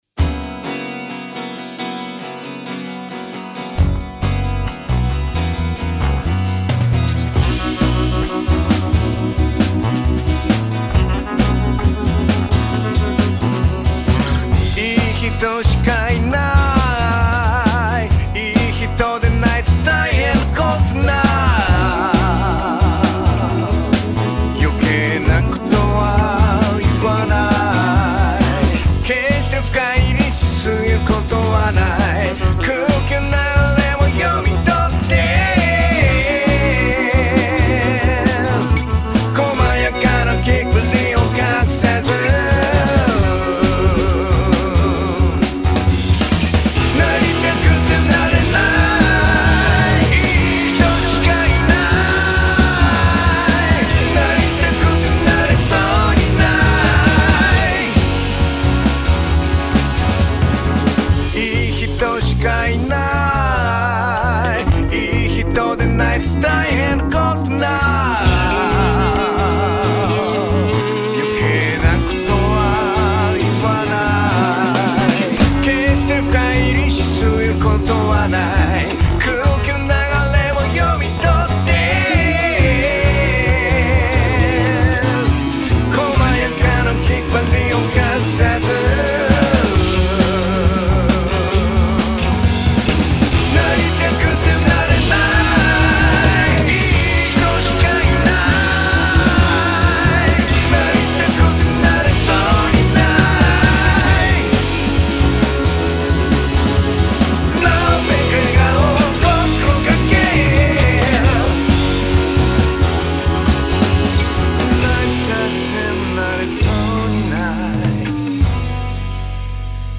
毎日1曲、新曲つくってアレンジ＆録音したものを日々アップロード中。